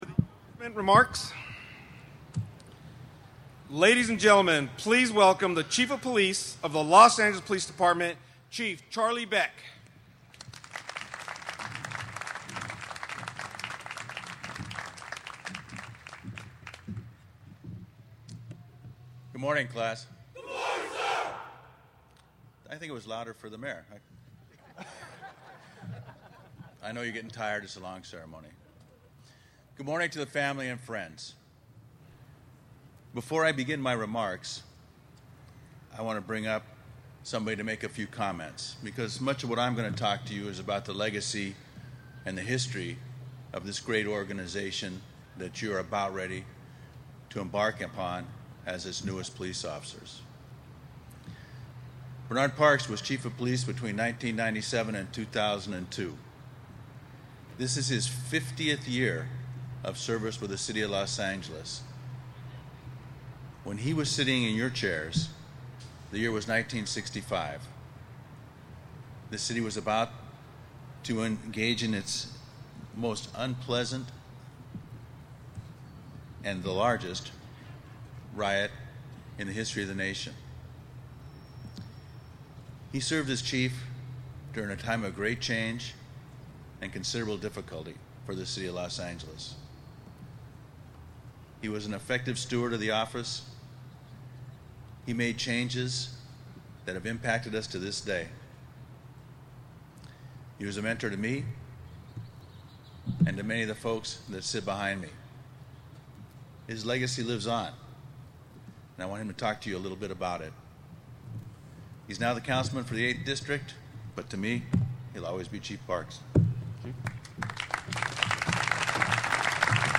cop-grad-speech-2-20-15.mp3